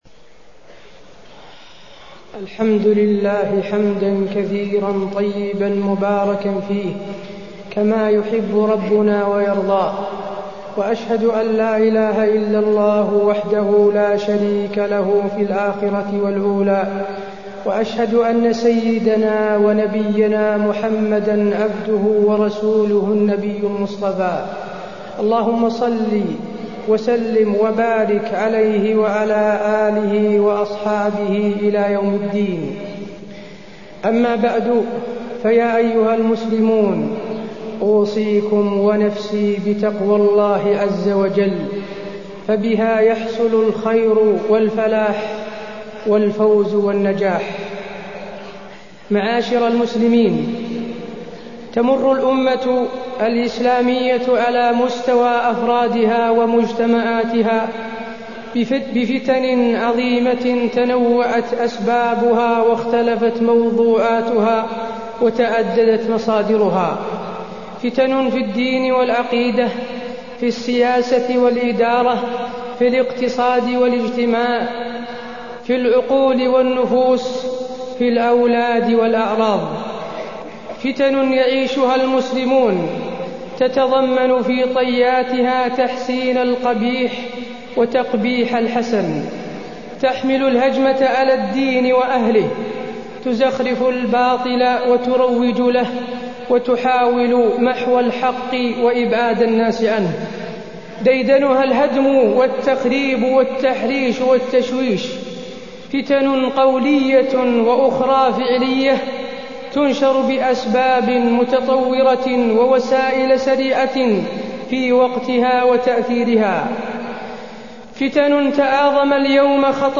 تاريخ النشر ١١ محرم ١٤٢٤ هـ المكان: المسجد النبوي الشيخ: فضيلة الشيخ د. حسين بن عبدالعزيز آل الشيخ فضيلة الشيخ د. حسين بن عبدالعزيز آل الشيخ الفتن وأثرها على المسلمين